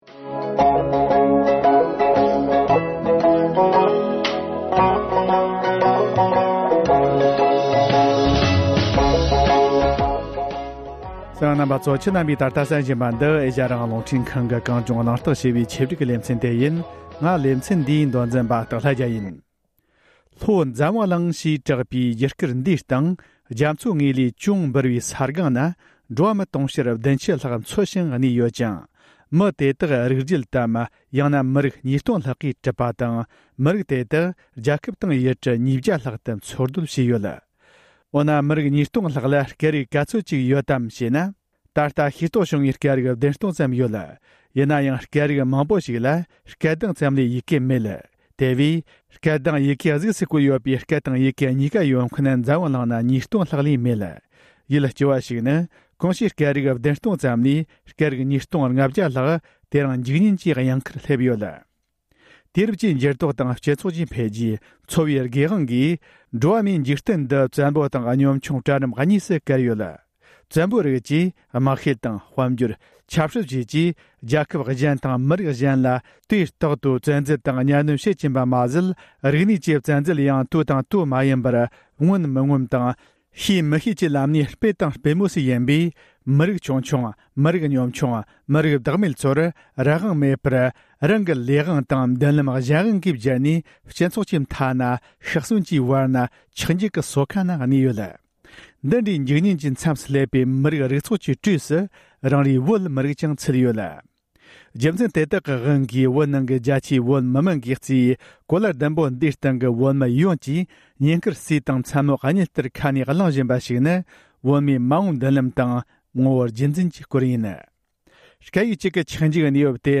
བོད་ནང་ལ་སྤྱི་སྐད་ཡོད་མེད་དང་དགོས་མིན་ཐད་གླེང་མོལ།